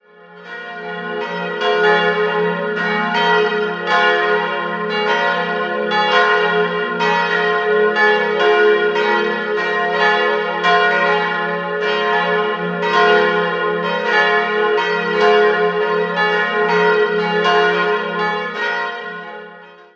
Innenansicht wird noch ergänzt. 3-stimmiges TeDeum-Geläute: f'-as'-b' Die Glocken wurden 1953 von Friedrich Wilhelm Schilling in Heidelberg gegossen.